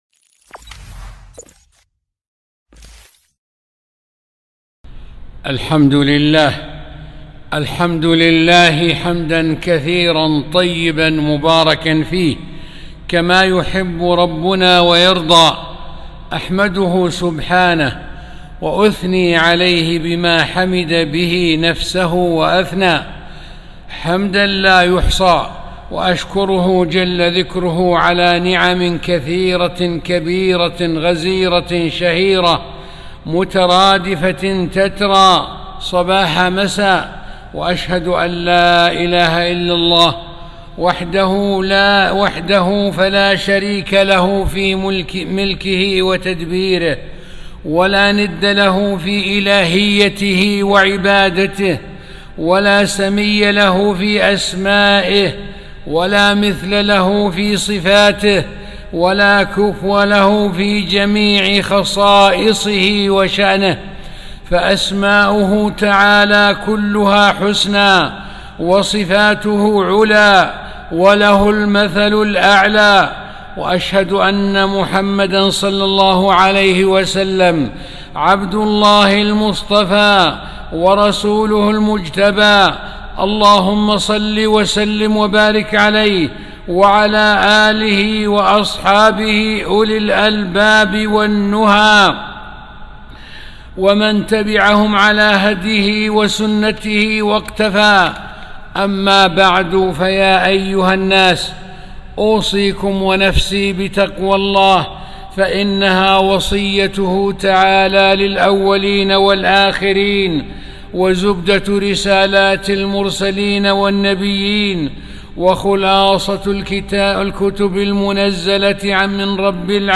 خطبة - موعظة وذكرى بشأن التقوى ومفاجأةِ فراق الحياة الدنيا